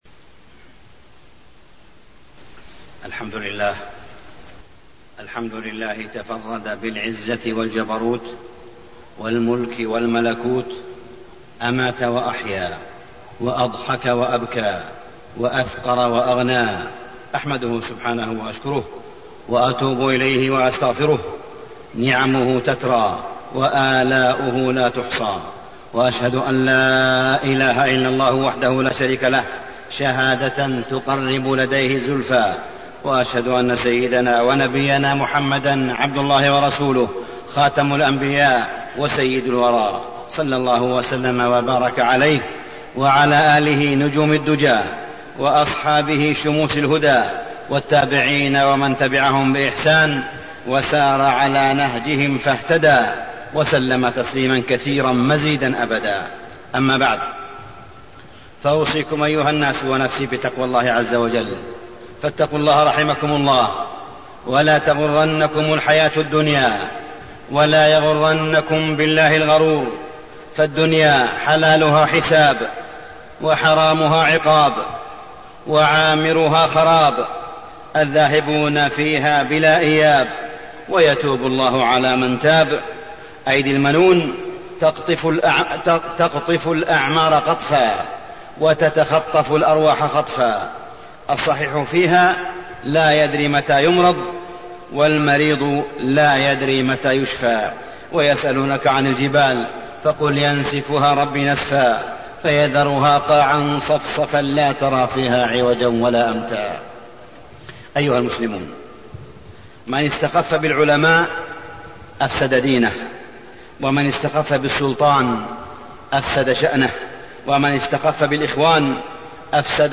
تاريخ النشر ٢٦ جمادى الآخرة ١٤٣٠ هـ المكان: المسجد الحرام الشيخ: معالي الشيخ أ.د. صالح بن عبدالله بن حميد معالي الشيخ أ.د. صالح بن عبدالله بن حميد الفئة الضالة بين العلم والجهل